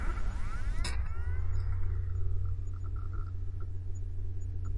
计算机 " 环境声音 笔记本电脑风扇
描述：哼唱笔记本风扇